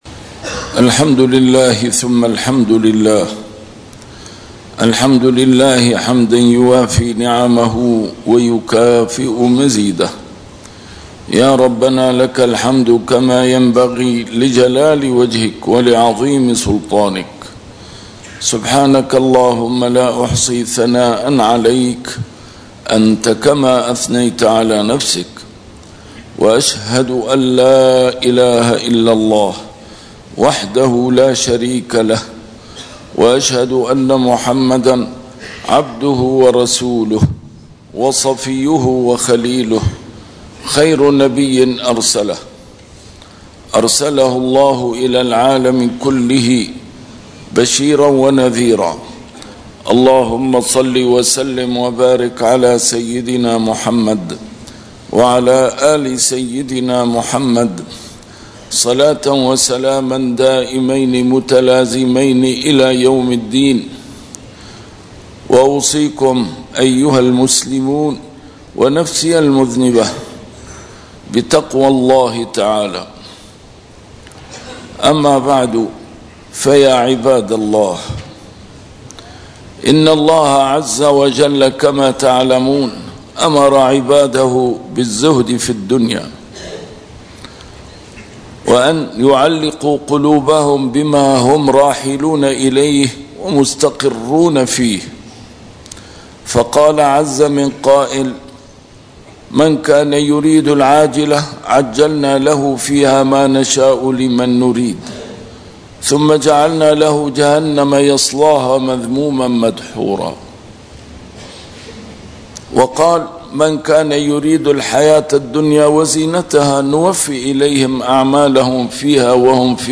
A MARTYR SCHOLAR: IMAM MUHAMMAD SAEED RAMADAN AL-BOUTI - الخطب - مفهوم الزهد في الدنيا